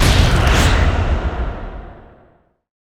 Venom_Damage_00.wav